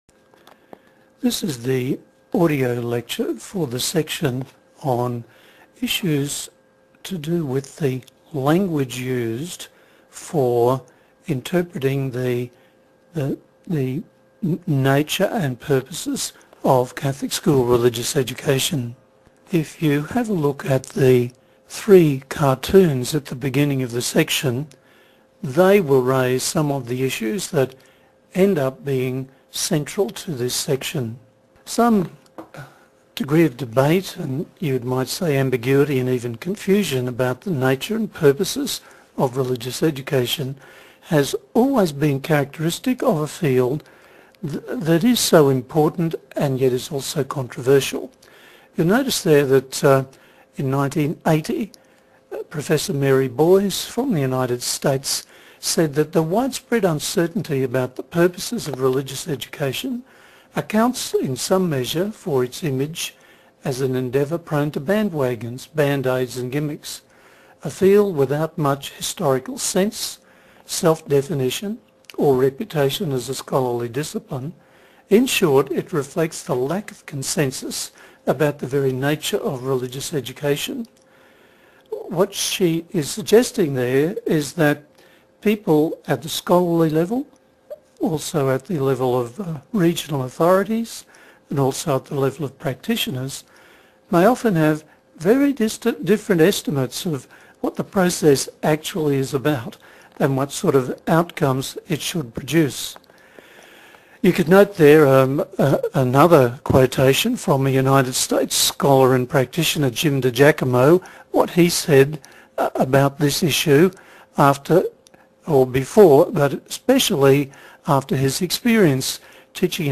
2. The audio file lecture Click the icon to hear or download the mp3 audio lecture file.